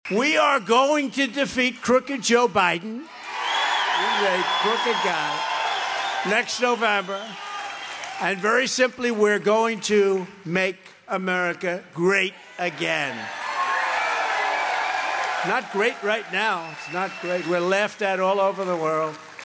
Following his official filing in Concord earlier in the day, he spoke at a rally Monday in Derry, New Hampshire. He had little to say about his Republican rivals and instead turned most of his fire on President Biden.